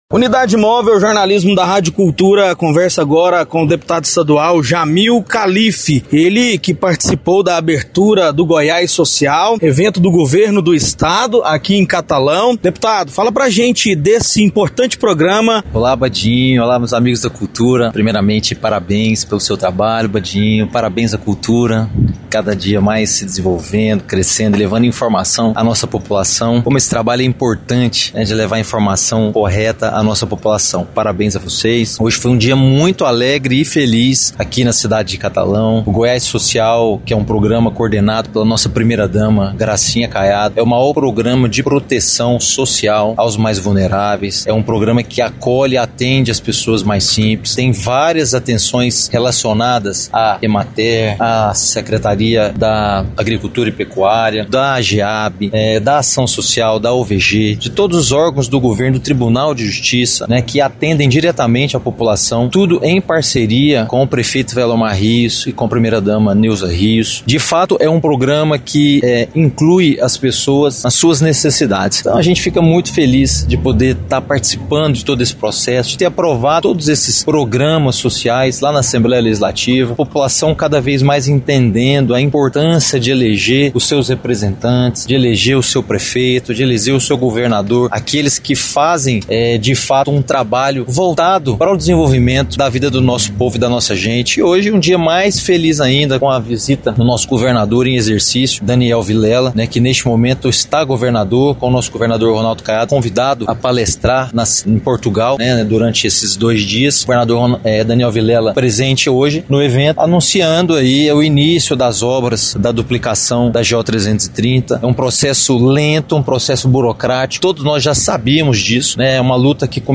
Em entrevista, Calife ressaltou que a amizade com Adib vai além da política e que a orientação do ex-prefeito tem sido fundamental para seu mandato.